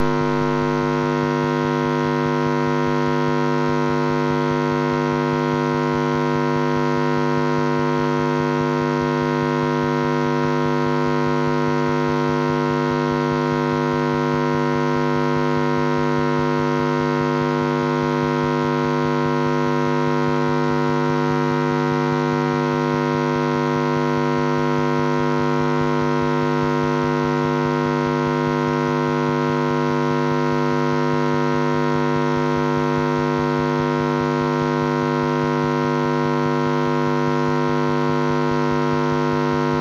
The dirty electricity filters though needed around the house will also make the process of monitoring much easier as the noise levels in the recording will be reduced drastically and hence the modulations become much clearer and easier to translate.
jack-and-twinkle-loop-receiver-on-GS-filter.mp3